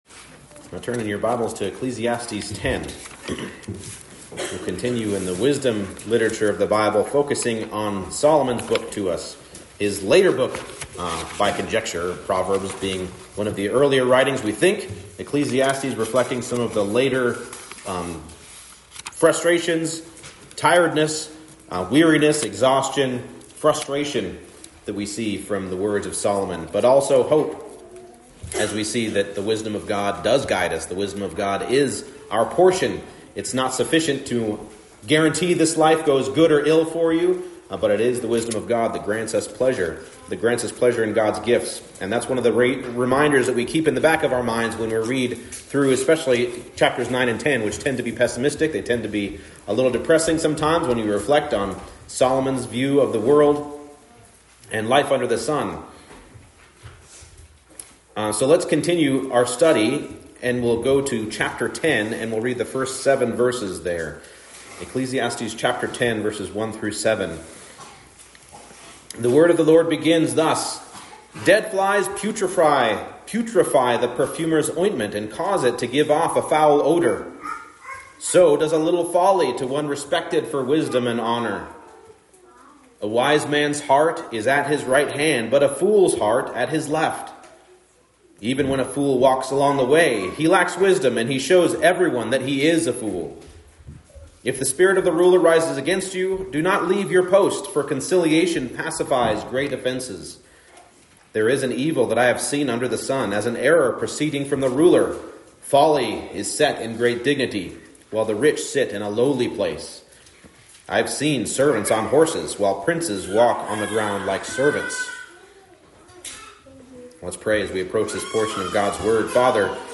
Ecclesiastes 10:1-7 Service Type: Morning Service Just a little folly can destroy a lot of good.